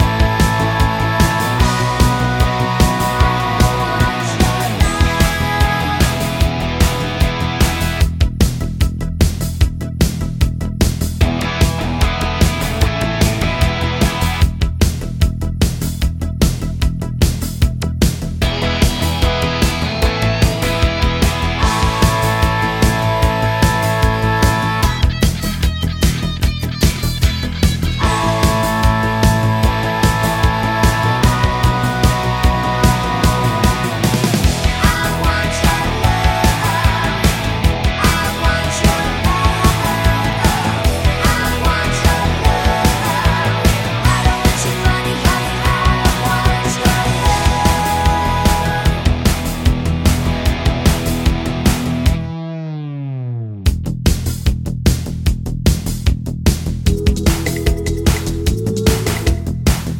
no Backing Vocals Indie / Alternative 3:29 Buy £1.50